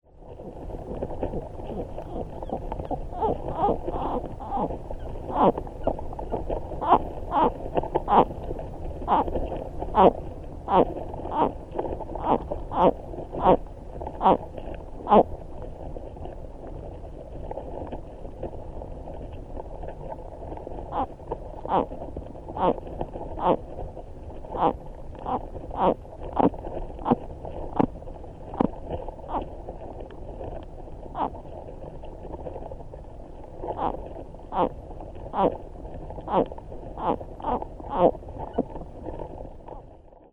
Water flowing from snow-melt into the small lake can be heard in the background, along with occasional singing birds.
Sound This is a 40 second recording of frog sounds recorded underwater. A pair of slowly-swimming frogs in amplexus disappeared from view behind a a log on the water.